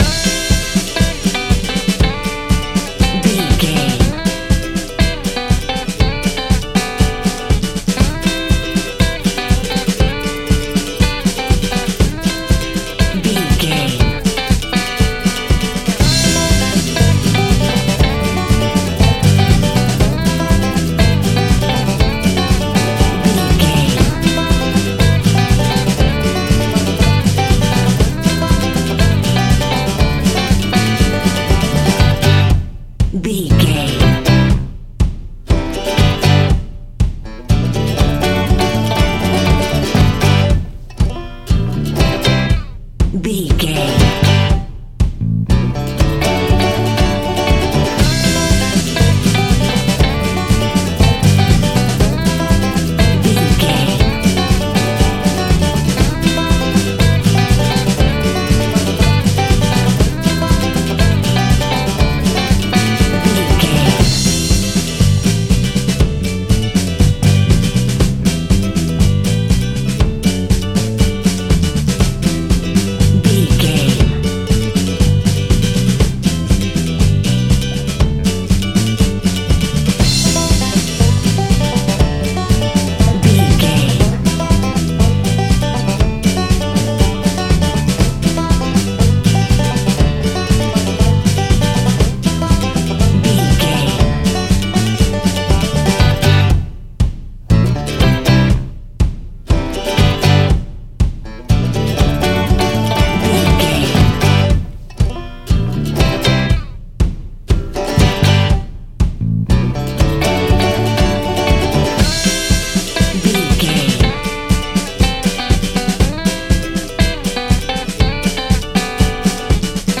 Uplifting
Ionian/Major
acoustic guitar
mandolin
ukulele
lapsteel
drums
double bass
accordion